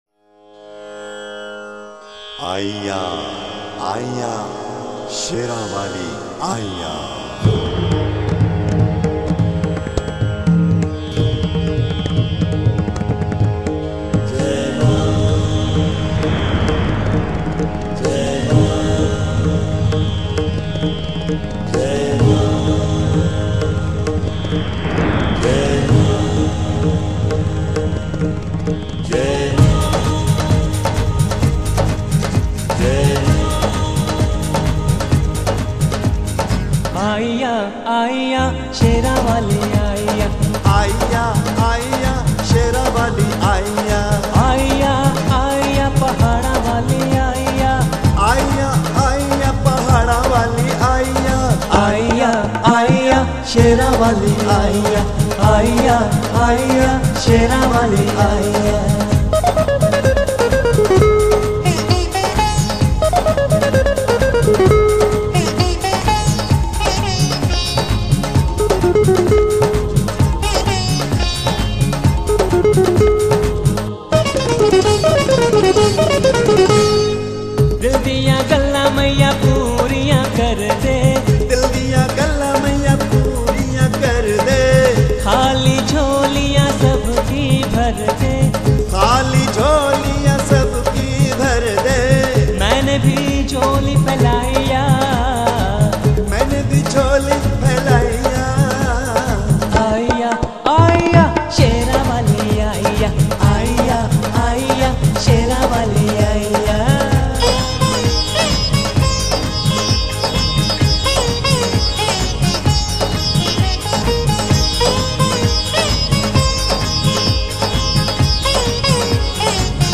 An Authentic Ever Green Sindhi Song Collection